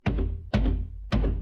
Sound effects
wall impact.mp3